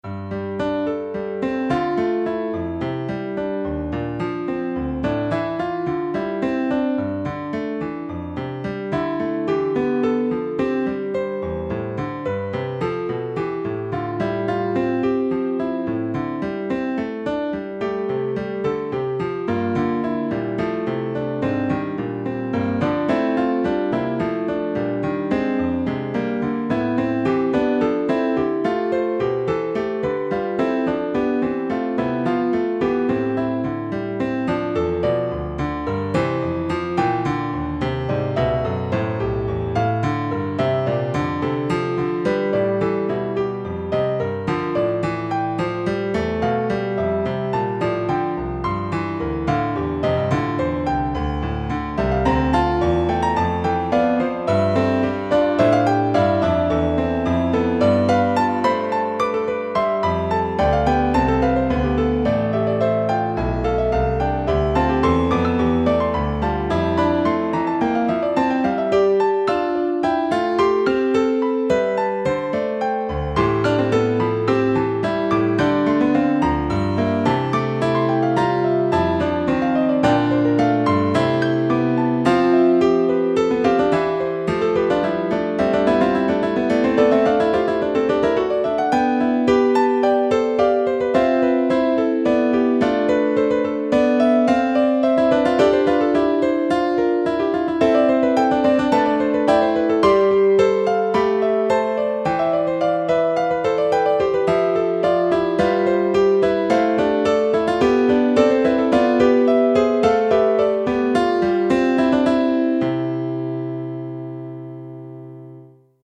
Als musikalische Metapher für den Jahreskreis dient hier der Quintenzirkel (die Anordnung der 12 Tonarten im nächsten Verwandtschaftsverhältnis).
Die Quinte (und als Komplementärintervall die Quarte) ist das konstituierende Intervall für die Begleitung / Kontrapunktierung der Liedmelodie.
Diese wiederum erklingt – in Entsprechung zu den vier Jahreszeiten – in den 4 Modi Original, Krebs, Umkehrung und Krebsumkehrung.